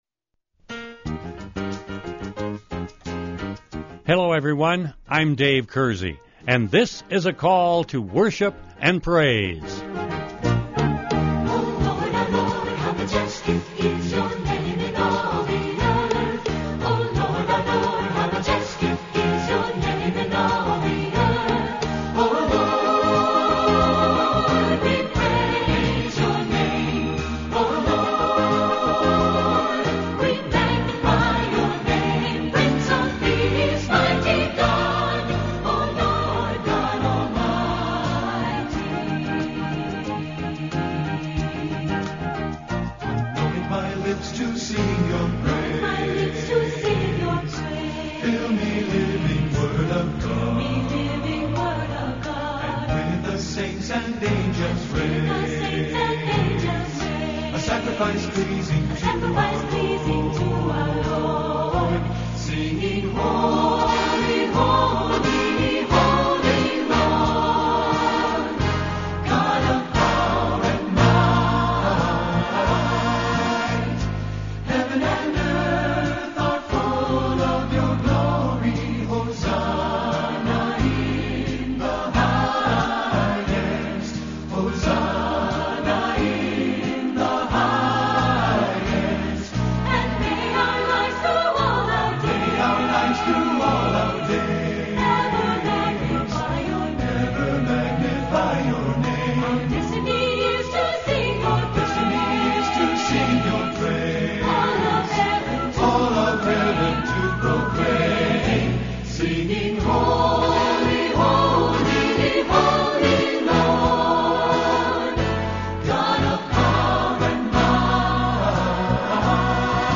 This week on Call To Worship we will be singing and talking not only about God’s Greatness, but about God’s Goodness.